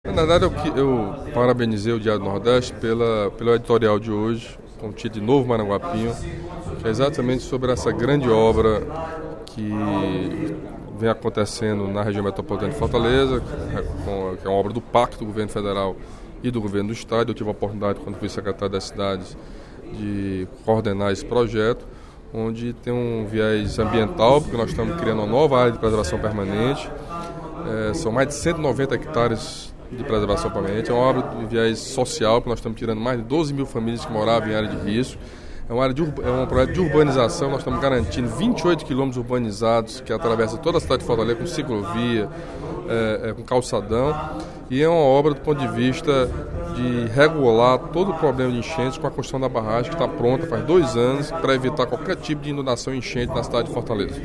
Durante o primeiro expediente da sessão plenária desta quinta-feira (05/06), o deputado Camilo Santana (PT) elogiou o editorial de hoje do jornal Diário do Nordeste, que trata do Rio Maranguapinho.